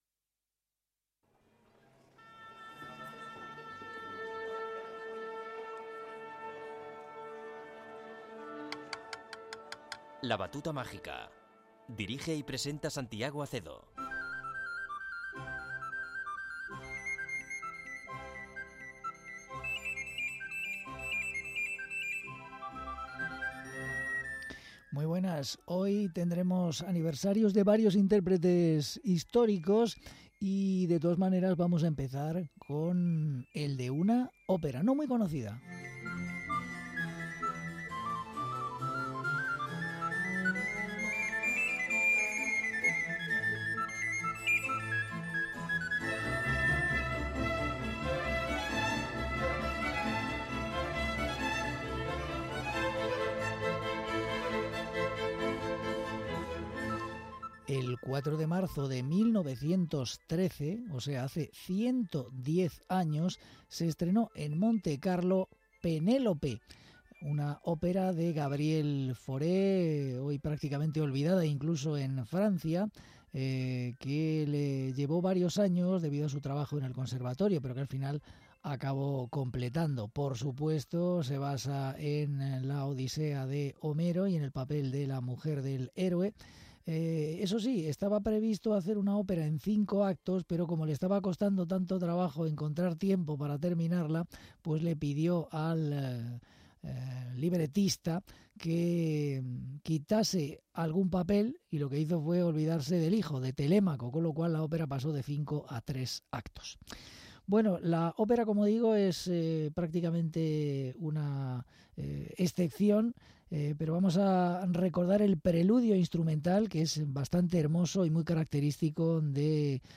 bajo-barítono
clavecinista
poema sinfónico